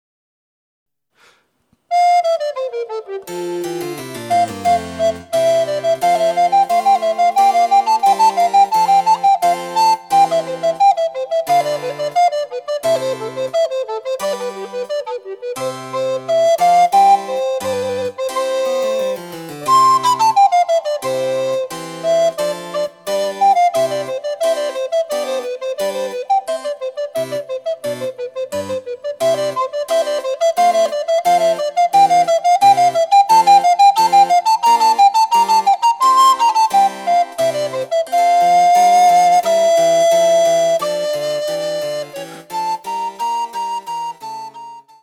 ■リコーダーによる演奏
リコーダー演奏
チェンバロ（電子楽器）演奏